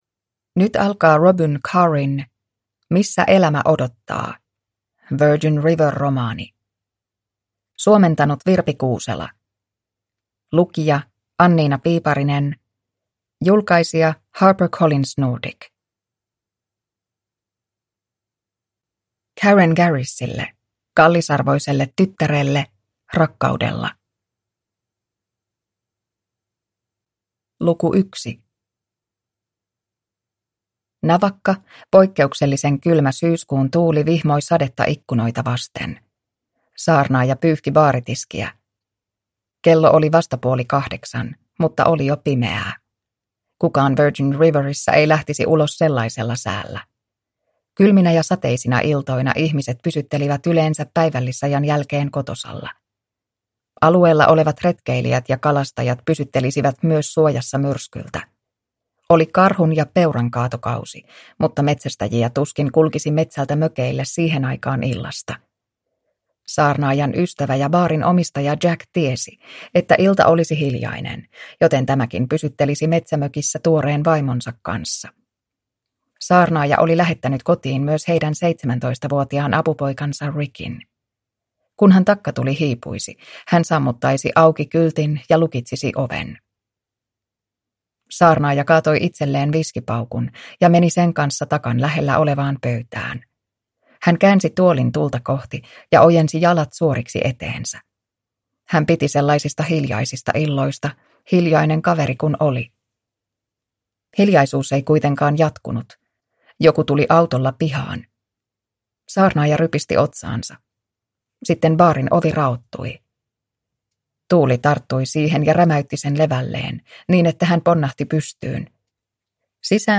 Missä elämä odottaa – Ljudbok – Laddas ner